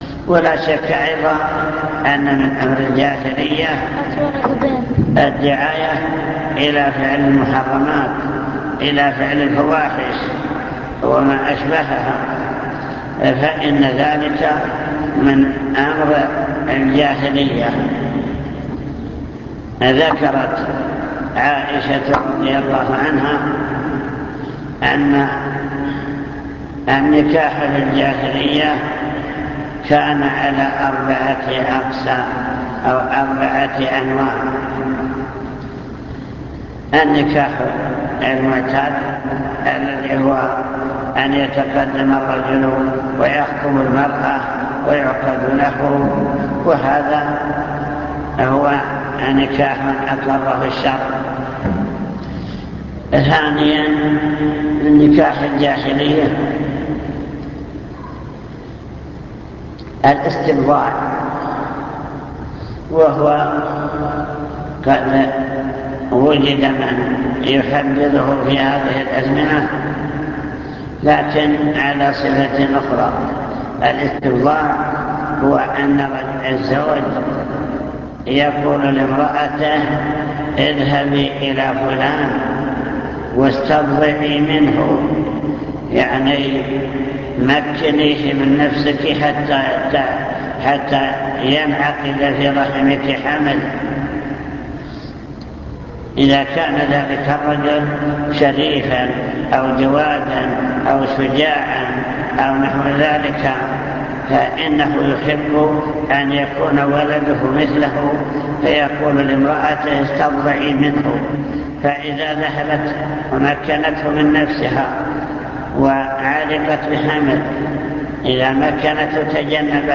المكتبة الصوتية  تسجيلات - محاضرات ودروس  مسائل الجاهلية